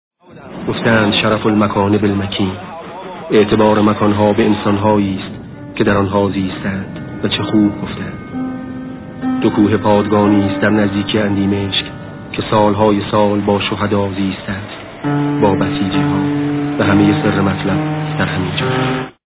صدای شهید آوینی/ دوکوه پادگانیست که سالهای سال با شهدا زیسته اند و همه سر مطلب همین جاست.